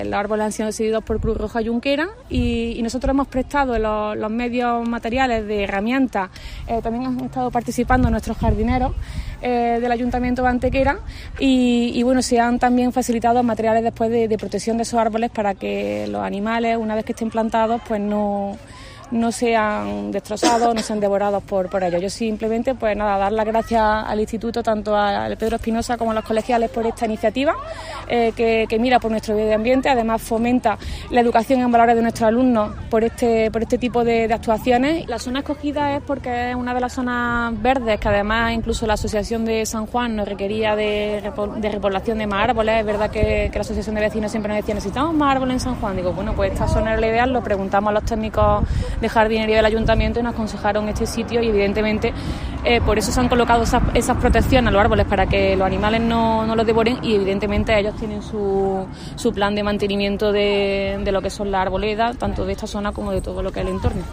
La teniente de alcalde de Urbanismo y Mantenimiento, Teresa Molina, y la concejal de Educación, Sara Ríos, han visitado en la mañana de hoy las labores de replantación de árboles que se están efectuando este jueves en una ladera del barrio de San Juan ubicada entre las calles Jesús, Santa María la Vieja y la carretera A-343.
Cortes de voz